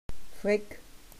Wörterbuch der Webenheimer Mundart